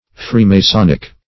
Freemasonic \Free`ma*son"ic\, a.